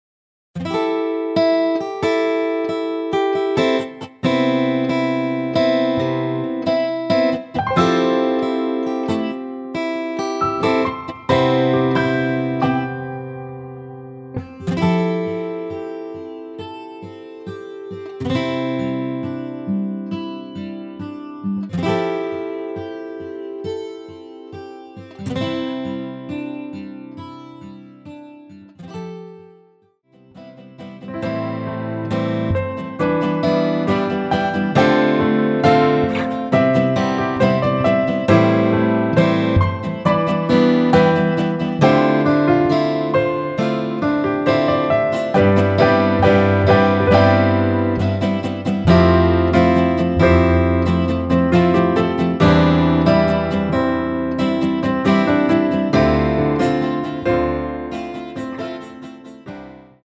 원키에서(+1)올린 MR 입니다.(미리듣기 참조)
앞부분30초, 뒷부분30초씩 편집해서 올려 드리고 있습니다.
중간에 음이 끈어지고 다시 나오는 이유는